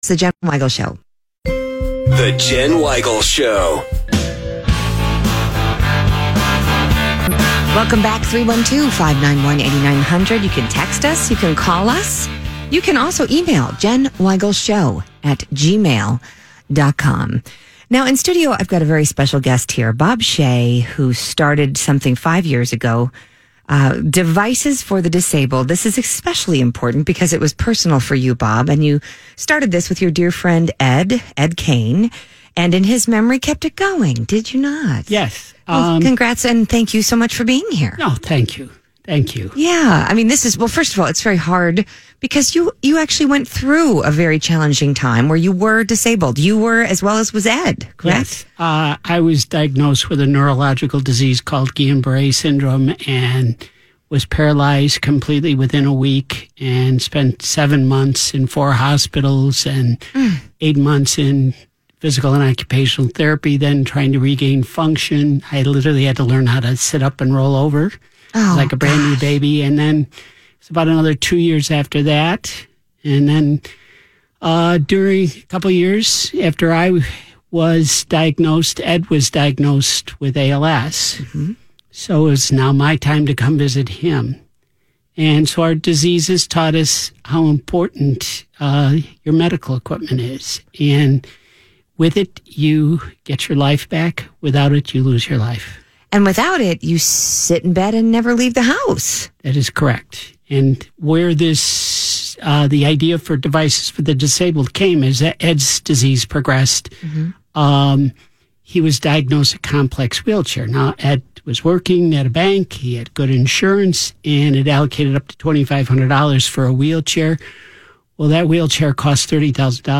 Listen to WLS-AM Interview